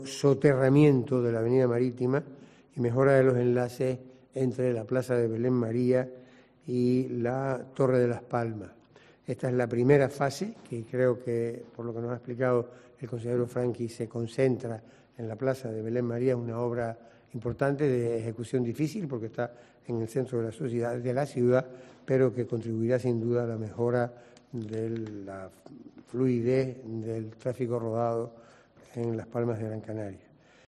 Julio Pérez, portavoz del Gobierno en funciones